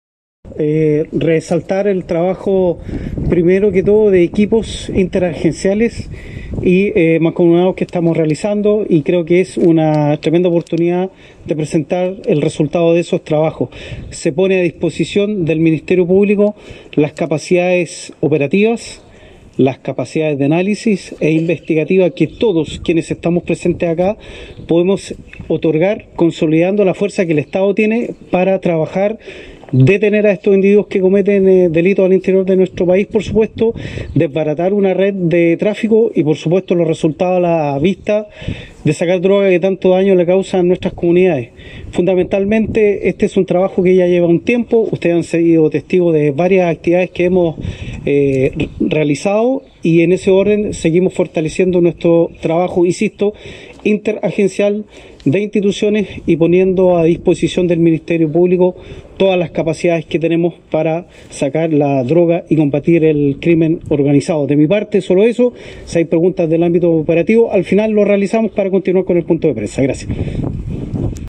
Al respecto, el Jefe de la V Zona de Carabineros, Edgar Jofré  destacó el trabajo mancomunado que permitió el éxito de este operativo: